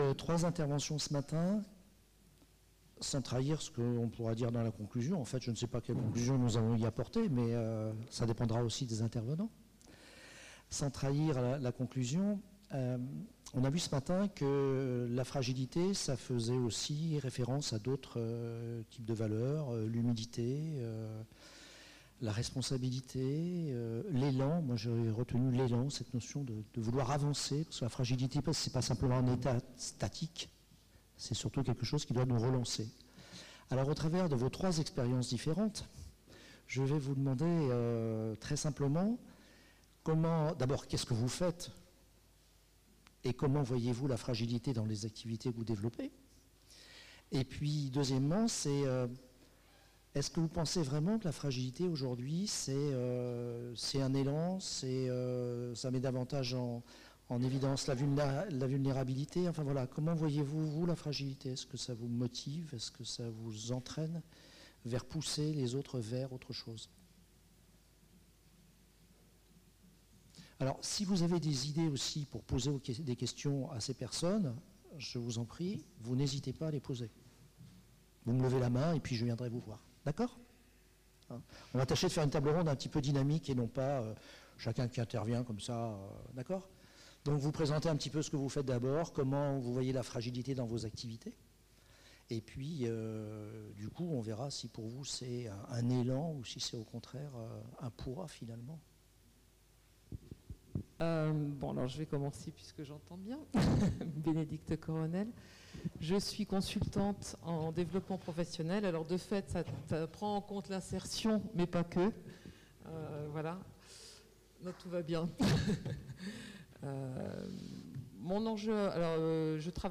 Fragilité - Après-midi - Table ronde — Pélerins Mont Saint Michel